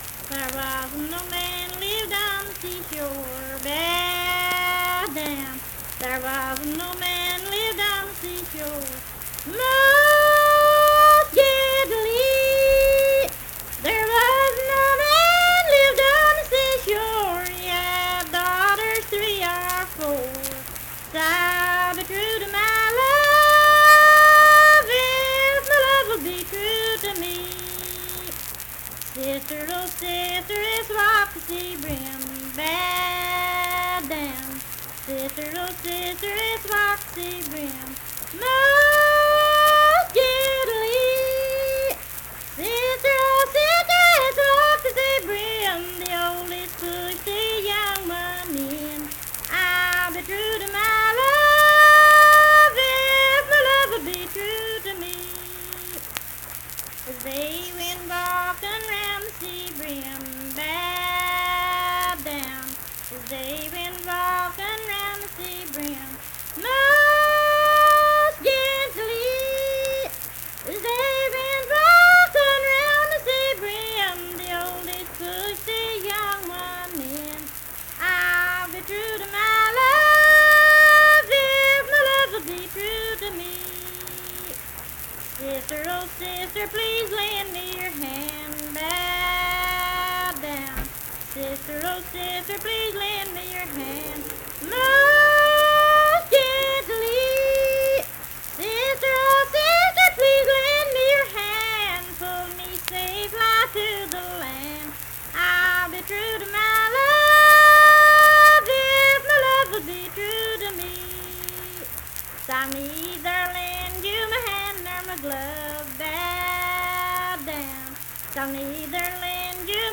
Unaccompanied vocal music
Verse-refrain, 7(8w/r).
Voice (sung)